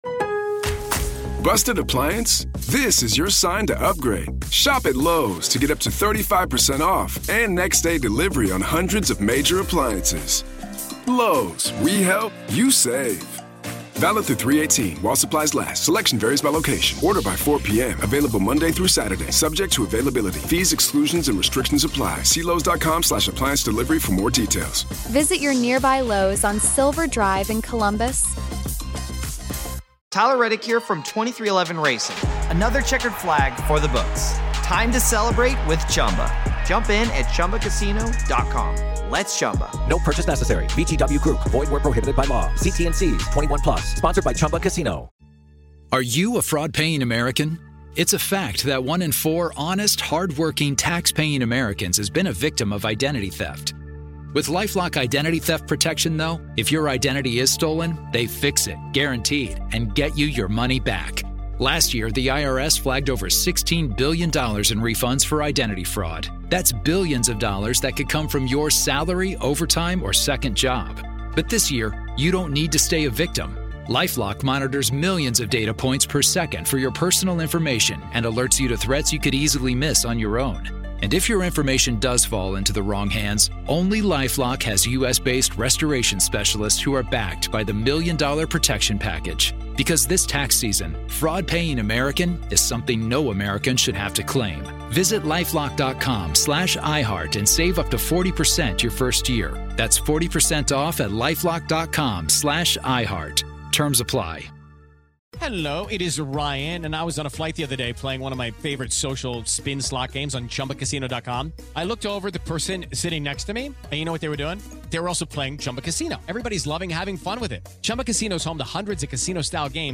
From shadow figures and strange sounds to the emotional weight that lingers in historic spaces, this conversation explores where preservation meets the paranormal—and how history and hauntings often occupy the same foundation.